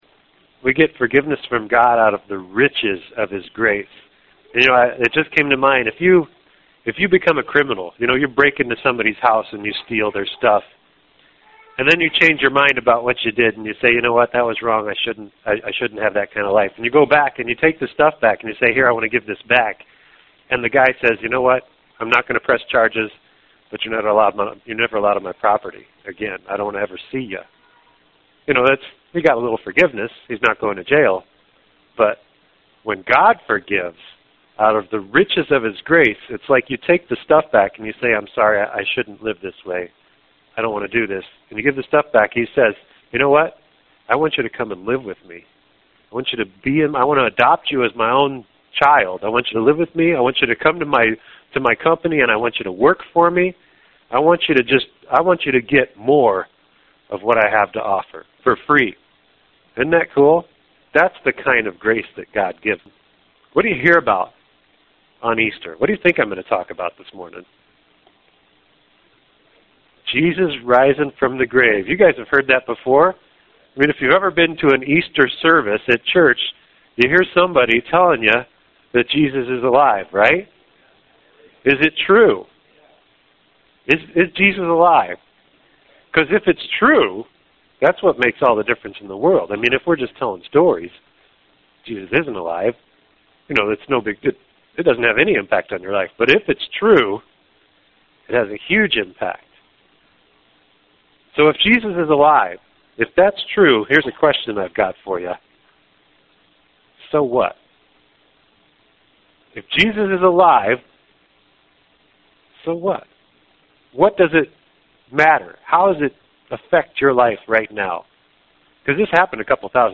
First Fruits: A Message for Easter